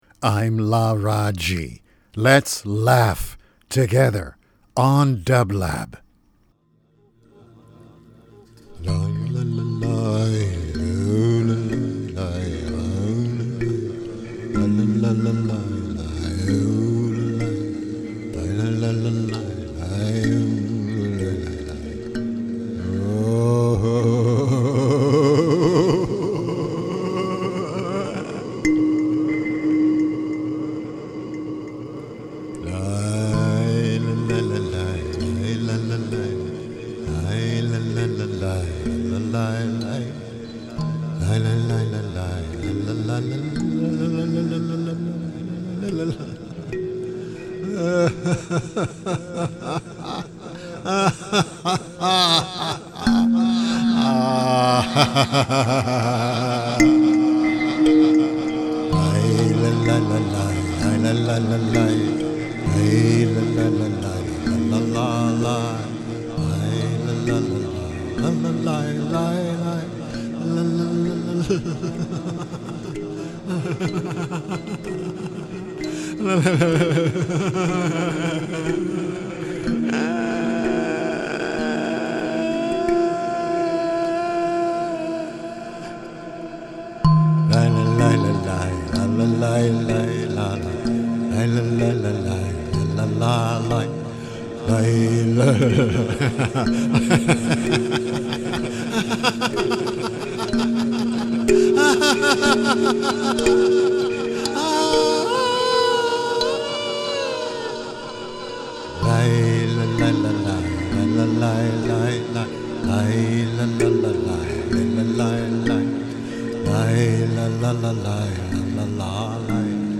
LARAAJI LAUGHS ~ a laughter meditation (08.10.23) - dublab
Every Thursday, Laraaji energizes the dublab airwaves with three minutes of luminous laughter flowing on celestial music beds on LARAAJI LAUGHS.